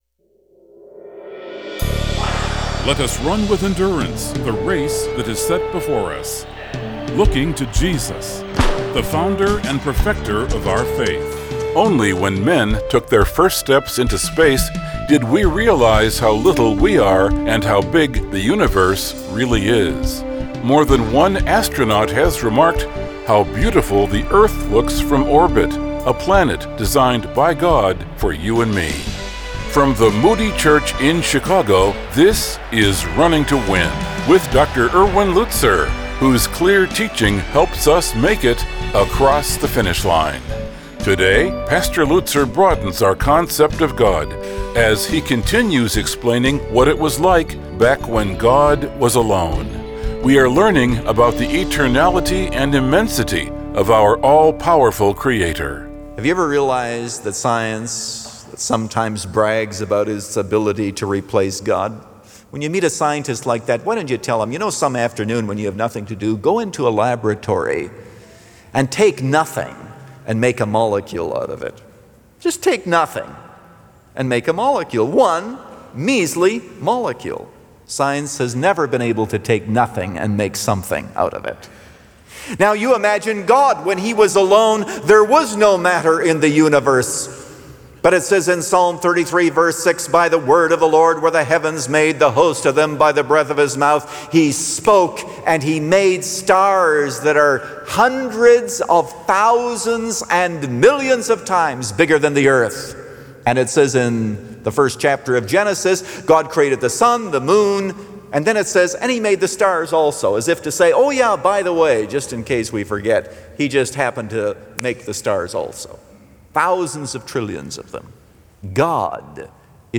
Today this program broadcasts internationally in six languages.